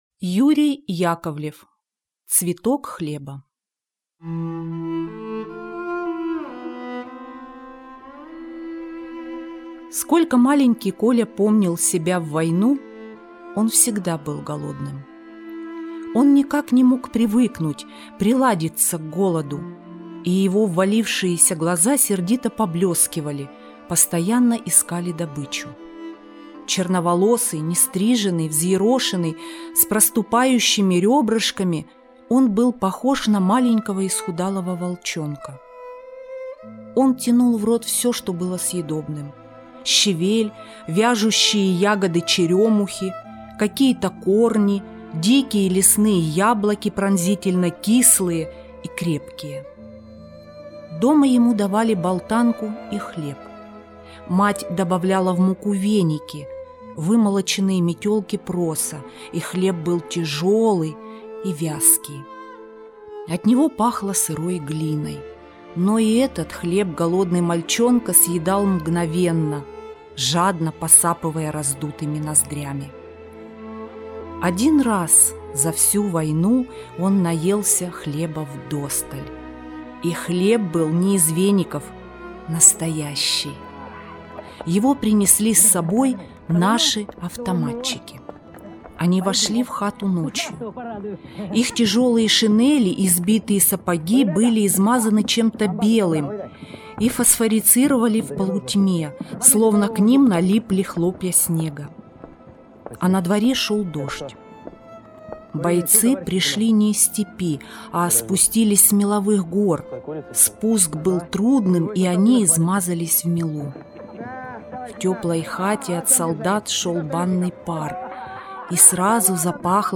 Цветок хлеба - аудио рассказ Яковлева - слушать онлайн